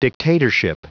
Prononciation du mot dictatorship en anglais (fichier audio)
Prononciation du mot : dictatorship